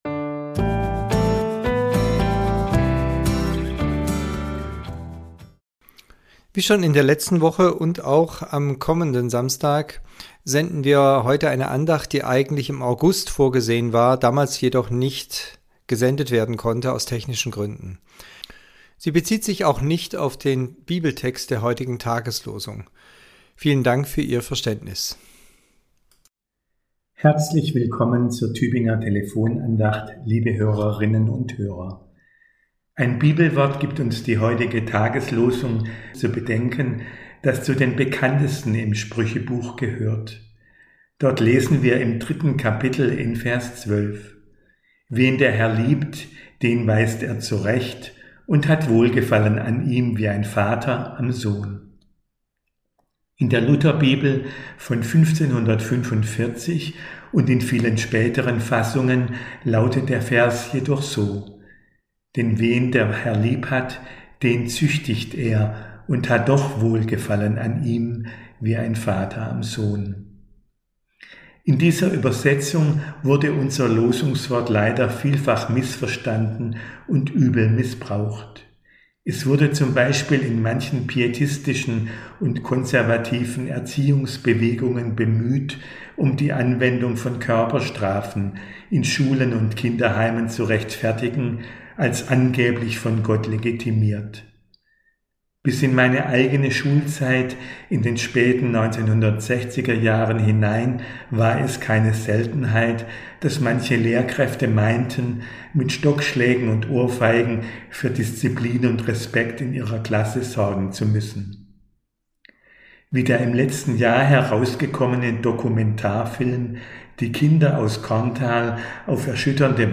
Andacht zur Tageslosung - ursprünglich zum 26.8.2025